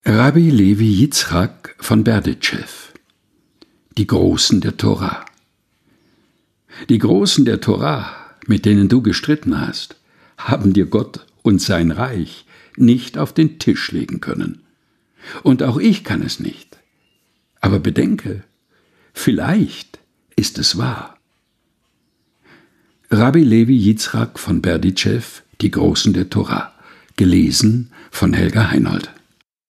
ausgesucht und im Dachkammerstudio vorgelesen